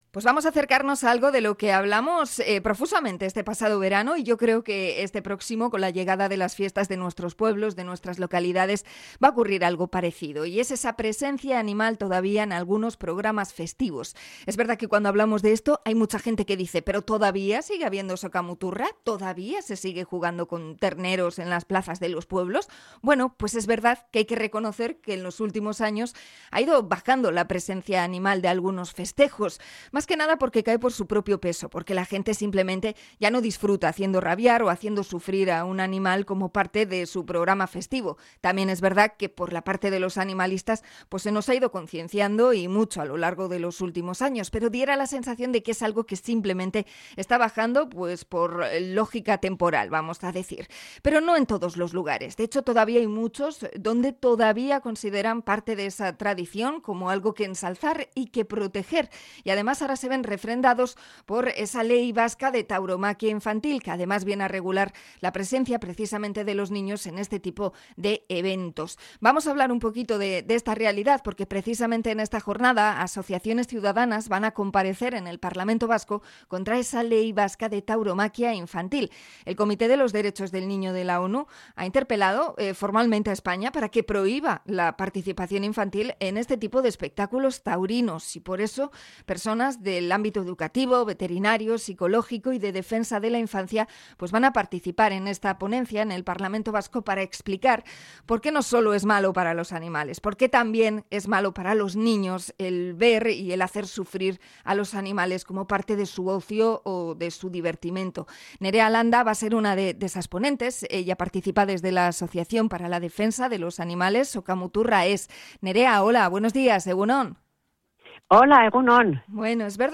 Entrevista a animalistas sobre la Ley de tauromaquia infantil